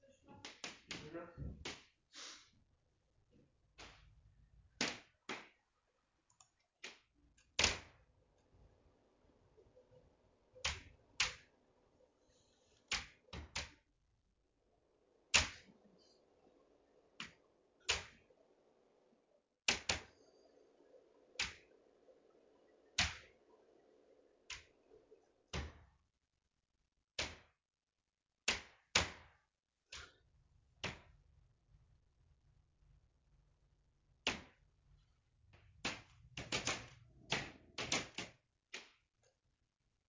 The sound also occurs with multiple snaps/pops when the heat is on and one of the exterior doors opens.
In some locations, the sound is a very slight popping sound but in other locations, it sounds like wood splitting.
The sounds can occur in multiple locations in rapid succession like an automatic weapon going off across the house.
SNAPPING CRACKING POPPING NOISE RECORDING [mp3 File]
Snapping-cracking-building-noise-383-BG.mp3